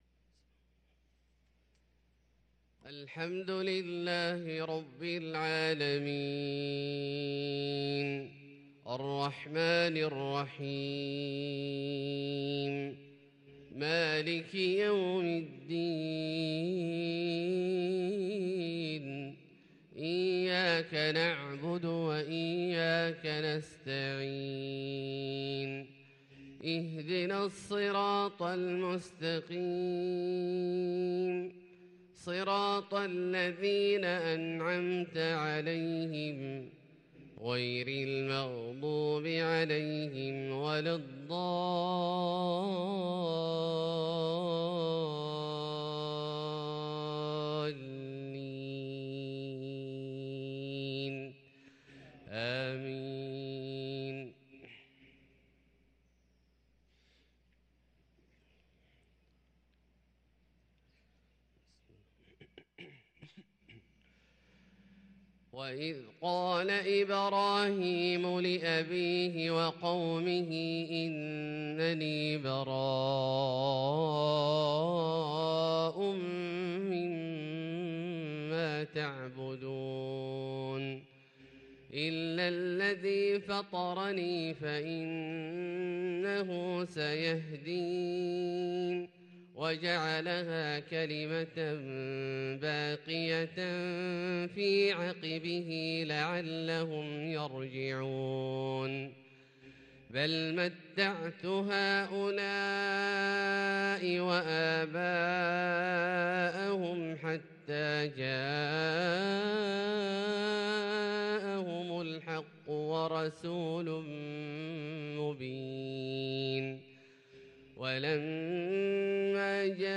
صلاة الفجر للقارئ عبدالله الجهني 25 جمادي الأول 1444 هـ
تِلَاوَات الْحَرَمَيْن .